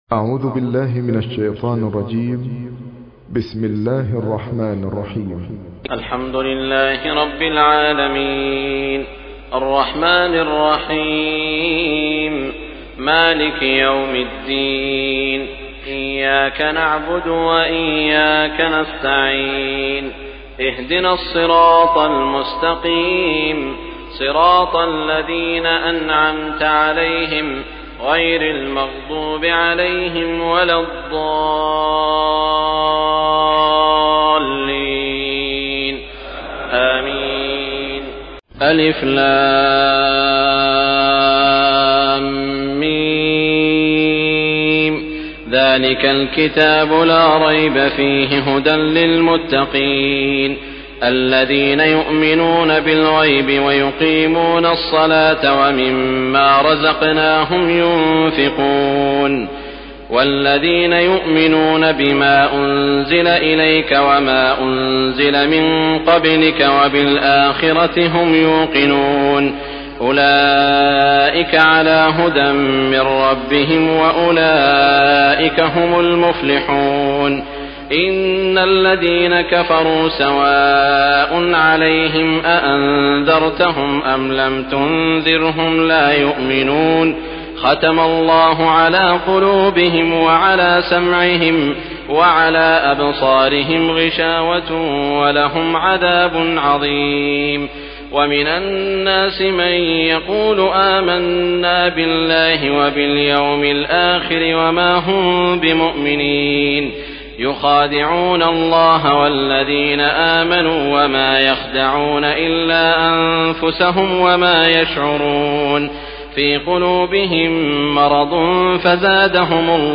تراويح الليلة الثانية رمضان 1418هـ من سورة البقرة (1-86) Taraweeh 2 st night Ramadan 1418H from Surah Al-Baqara > تراويح الحرم المكي عام 1418 🕋 > التراويح - تلاوات الحرمين